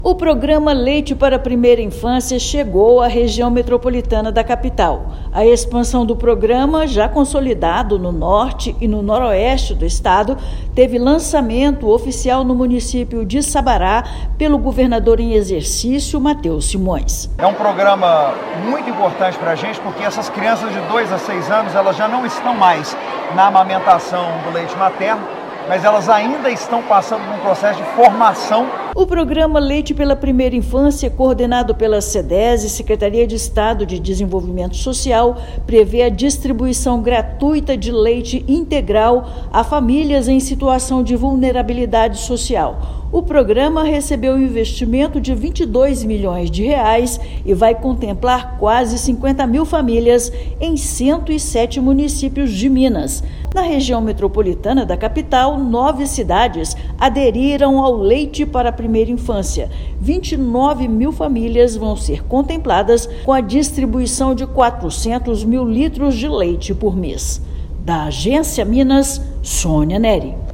[RÁDIO] Governo de Minas expande Programa Leite para a Primeira Infância à Região Metropolitana de Belo Horizonte
Cerca de 29 mil famílias serão contempladas com 3 litros de leite por semana na RMBH. Ouça matéria de rádio.